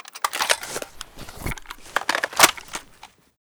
ddmv_reload.ogg